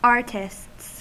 Uttal
Uttal US Okänd accent: IPA : /ˈɑɹt.ɪsts/ Ordet hittades på dessa språk: engelska Ingen översättning hittades i den valda målspråket.